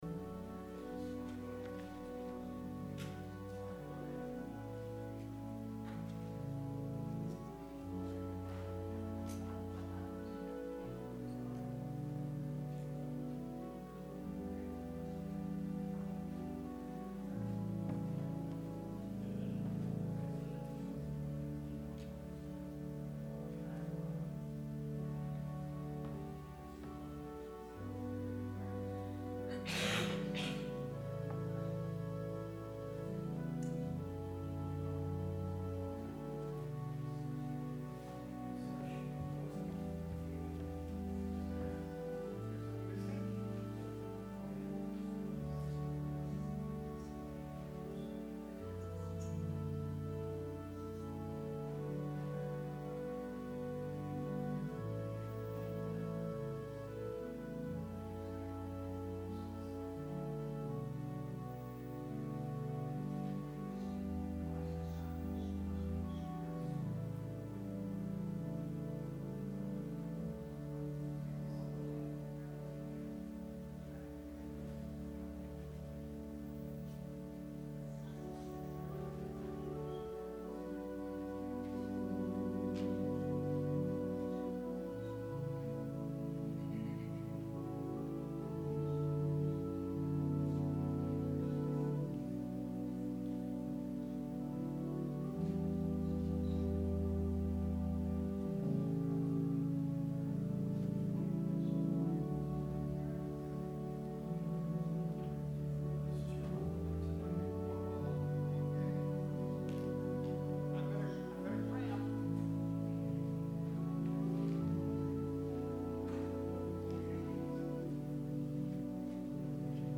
Sermon – September 29, 2019